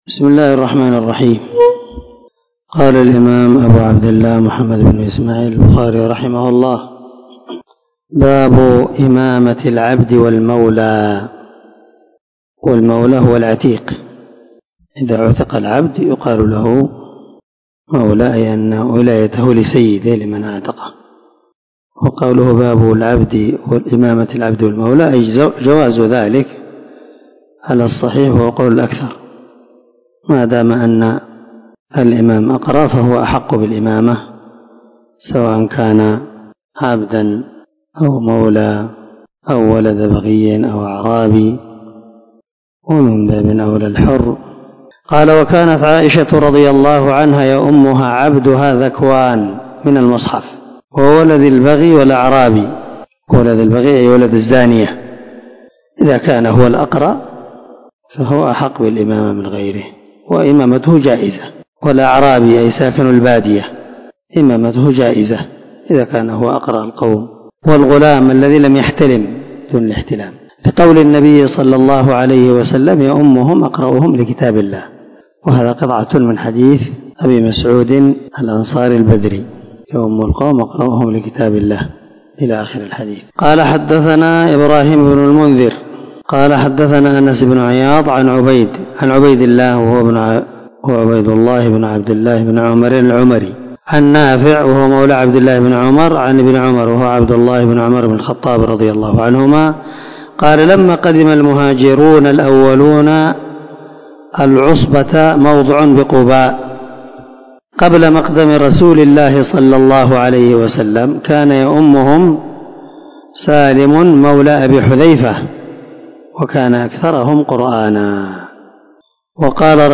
466الدرس 49من شرح كتاب الأذان حديث رقم ( 692 – 693 ) من صحيح البخاري
دار الحديث- المَحاوِلة- الصبيحة.